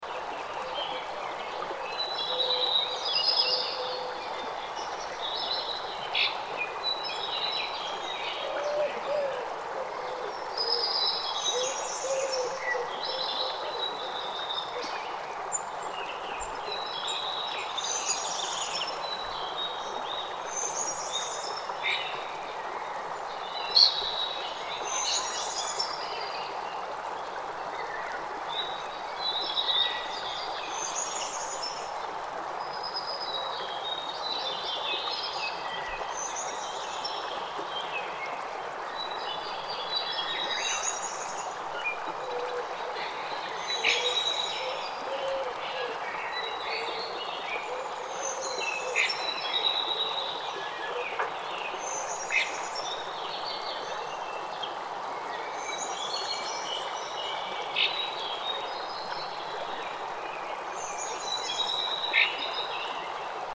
Forest Spring Ambient Sound Effect – Relaxing Nature Sounds
Immerse yourself in a peaceful spring forest with this forest spring ambient sound effect.
Enjoy birds chirping, rustling leaves, and flowing streams.
Forest-spring-ambient-sound-effect-relaxing-nature-sounds.mp3